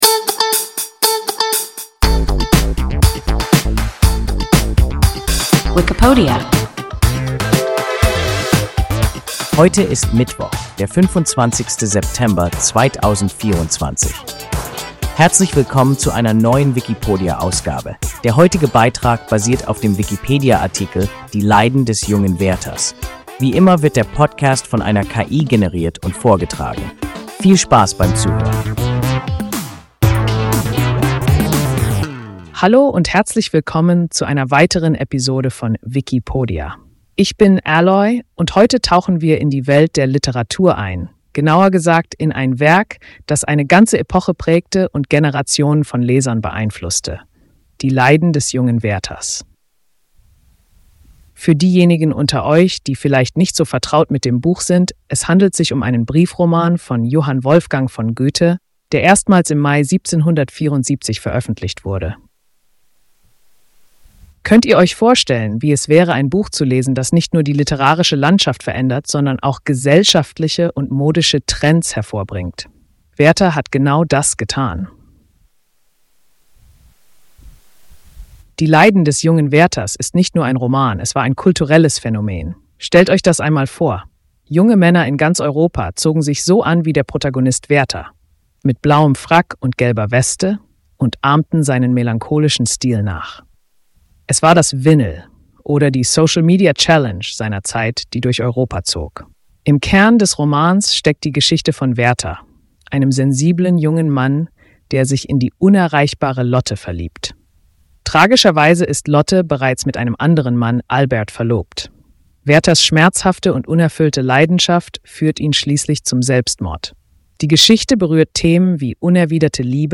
Die Leiden des jungen Werthers – WIKIPODIA – ein KI Podcast